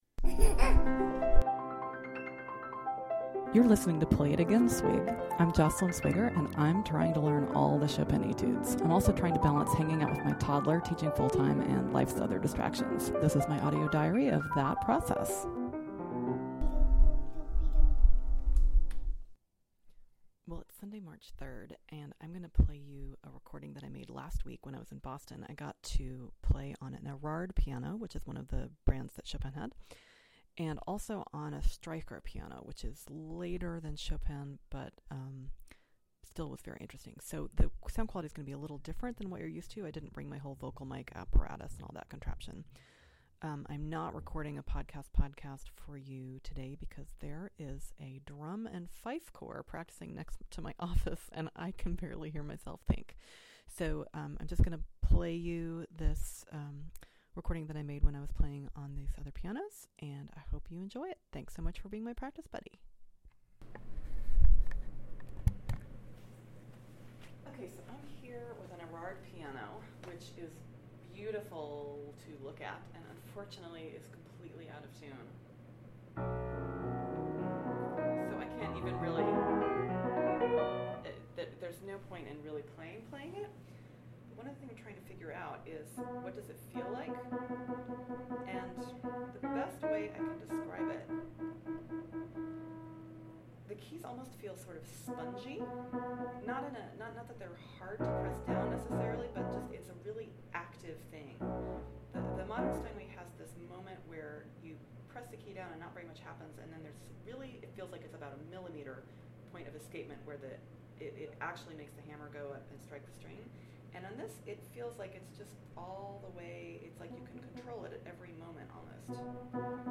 A brief encounter with an out-of-tune Erard piano (and another piano). Warning: questionable sound quality! horrible intonation!